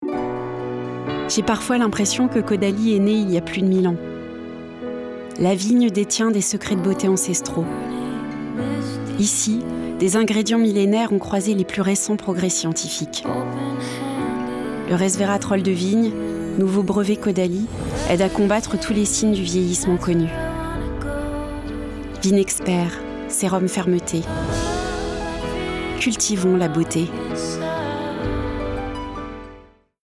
Caudalie Publicité
Comédienne